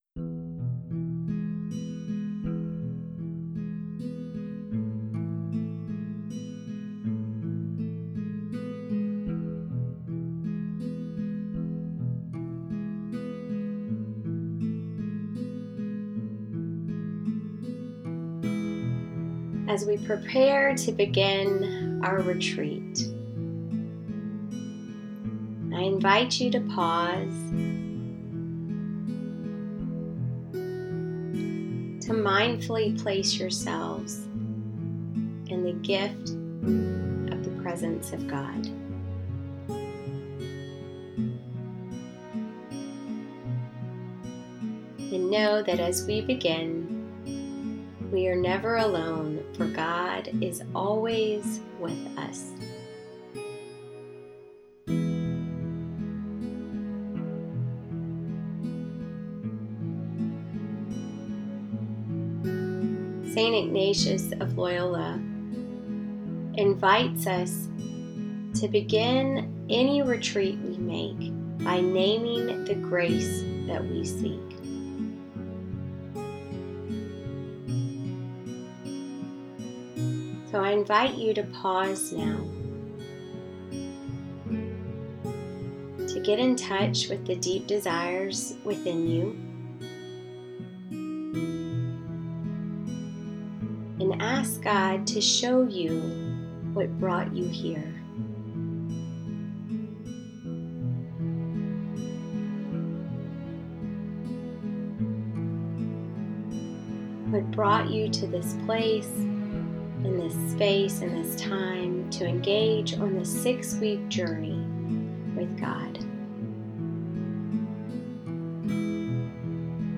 Pray with The Grace I Seek guided audio reflection.